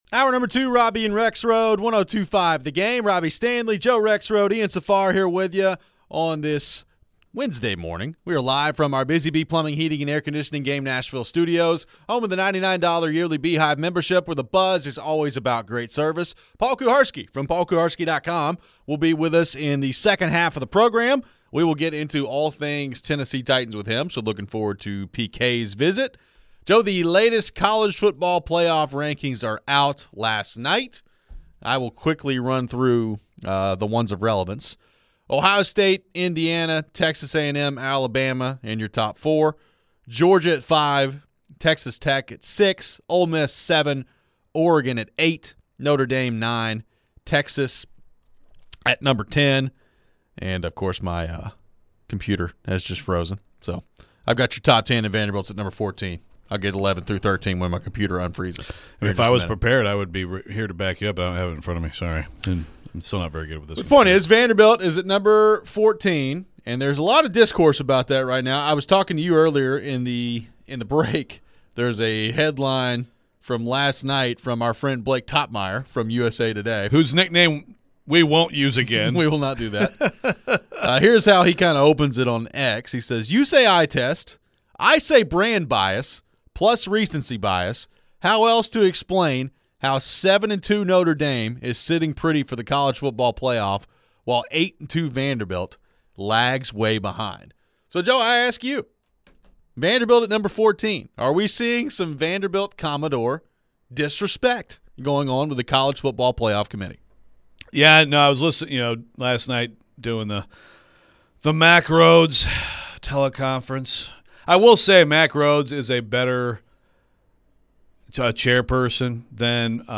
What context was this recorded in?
We take your phones. Could Ole Miss win the national championship?